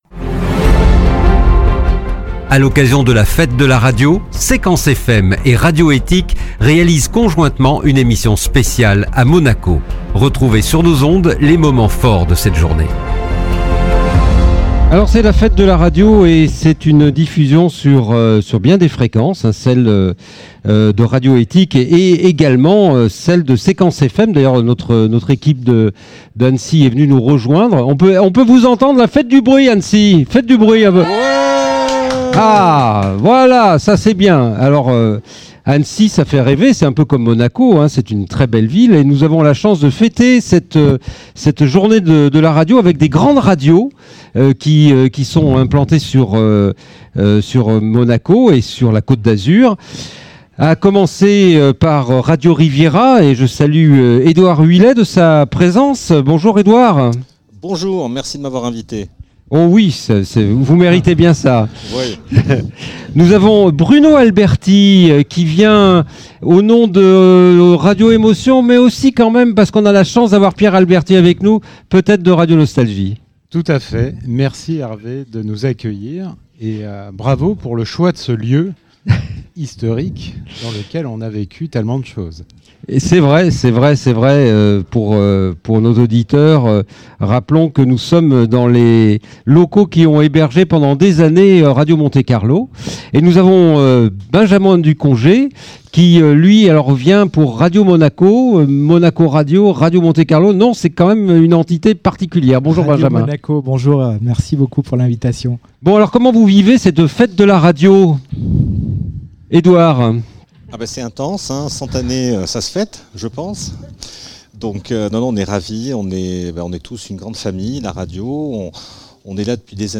Un sympathique moment d'échange entre ces différentes stations sur leurs différents médias avec leurs spécificités et leurs projets.